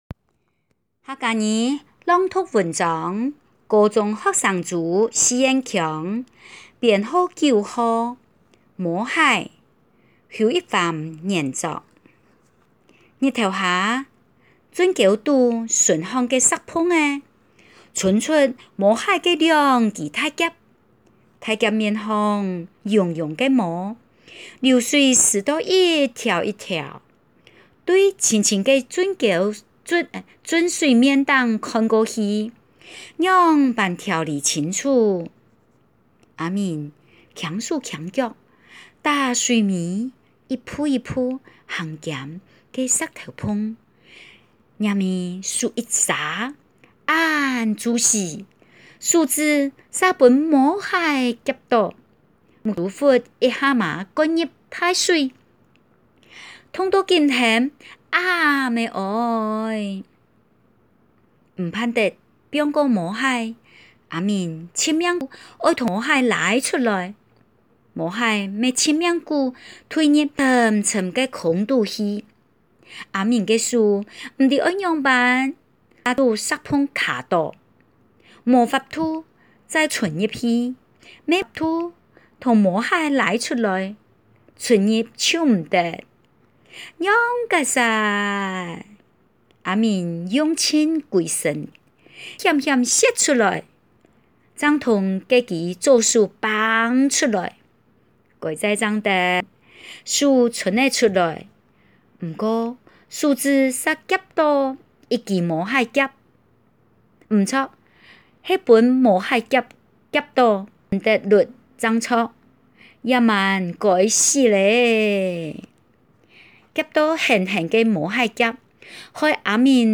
107學年度校內國語文競賽，客家語示範語音檔(四縣腔) | 家長資訊專區 | 左營高中